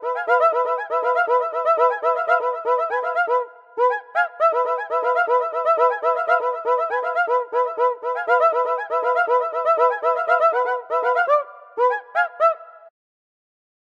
Brazilian Cuica Drum (C2-B2)
Drumwavy-32-Brazilian-Cuica-Drum-C2-B2.mp3